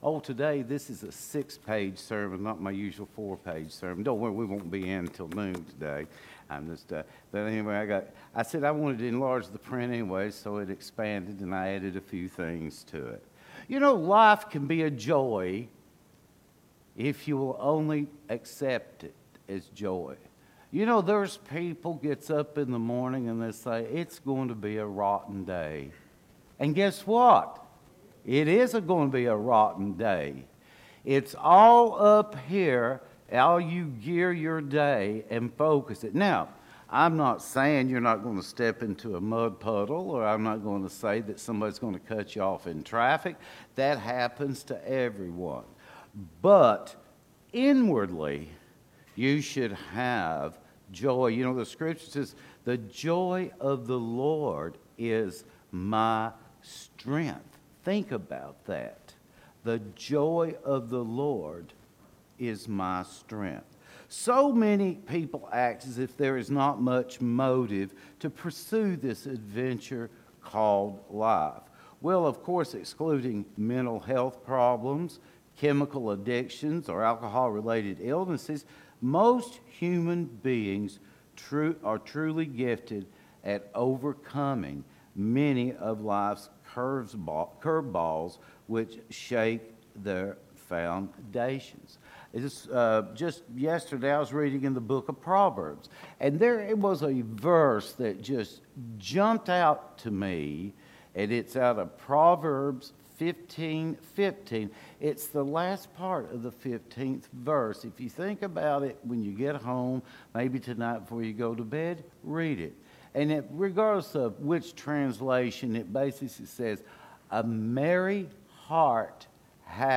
Sermon Tags